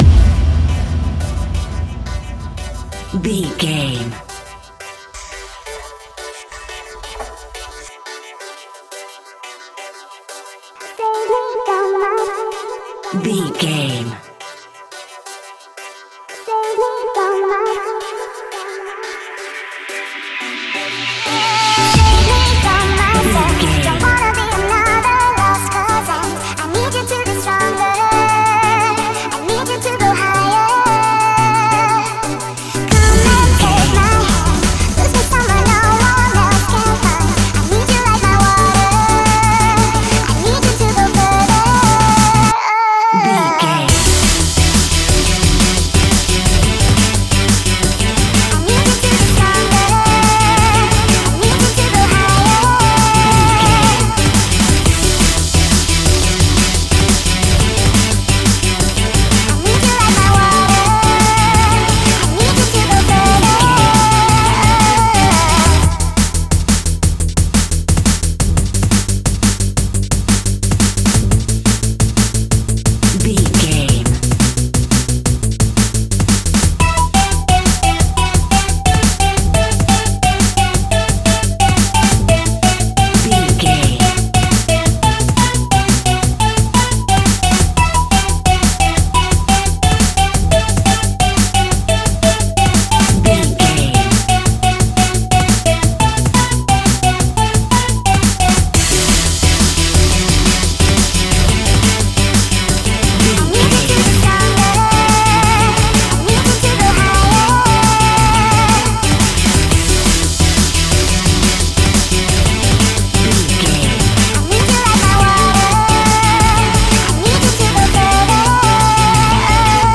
Epic / Action
Fast paced
Aeolian/Minor
aggressive
powerful
dark
energetic
intense
driving
futuristic
synthesiser
vocals
drum machine
Drum and bass
electronic
sub bass
Neurofunk
synth leads
synth bass